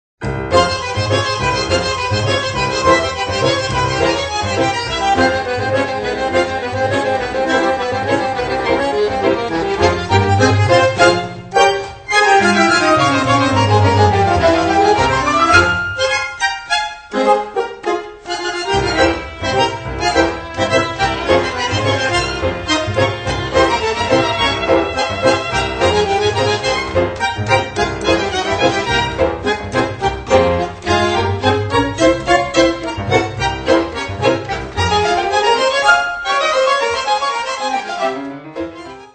Einige gern gehörte/ getanzte Milonga-Stücke